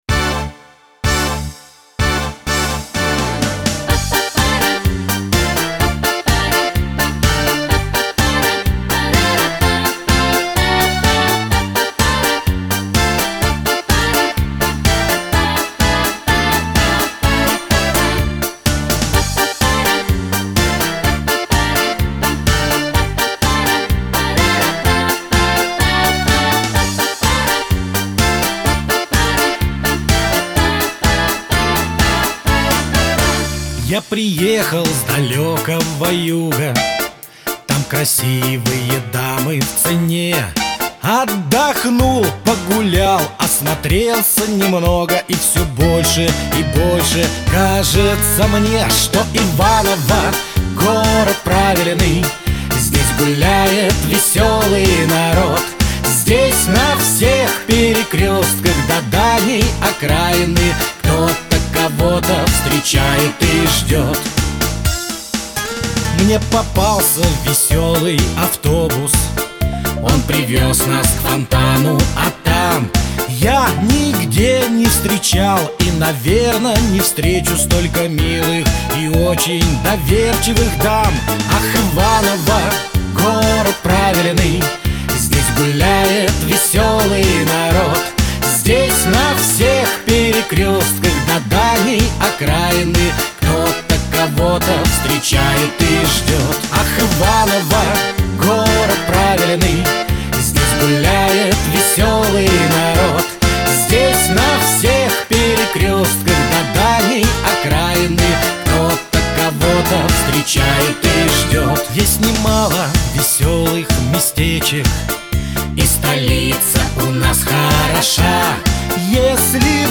бэк-вокал